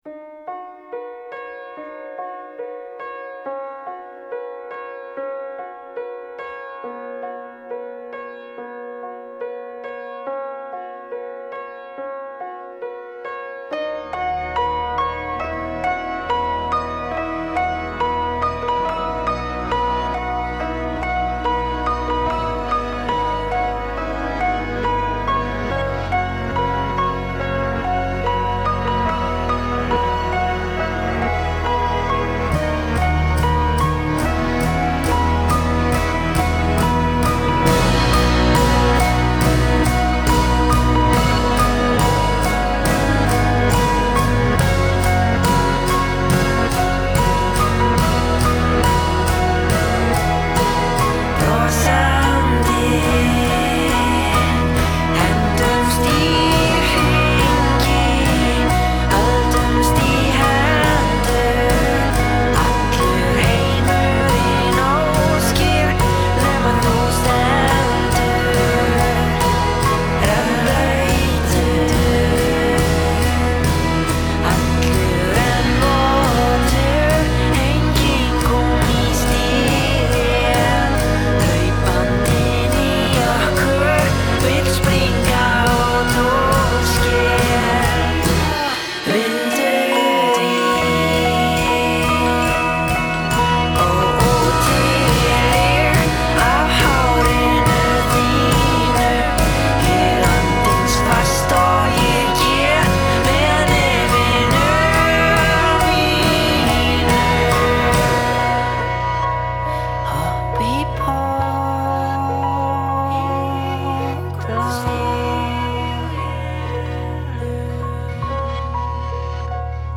genre: post_rock